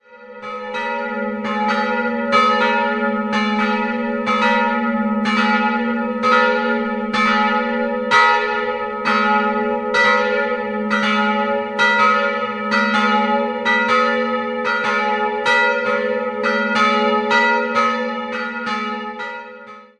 Die heutige Ausstattung ist weitgehend neuromanisch. 2-stimmiges Geläute: as'-b' Die größere Glocke wurde 1954 von Georg Hofweber in Regensburg gegossen, die kleinere stammt aus dem Jahr 1923.